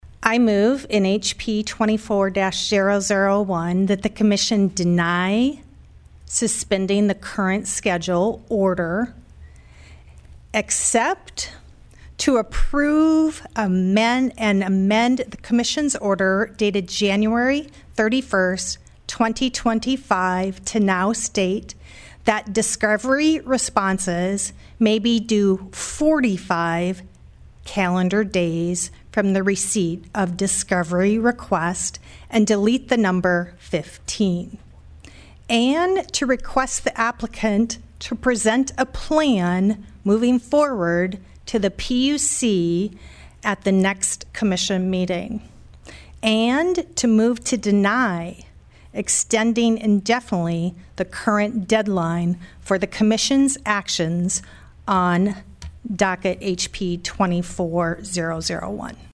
PIERRE, S.D.(HubCityRadio)- The South Dakota Public Utilities Commission held their meeting Thursday in Pierre.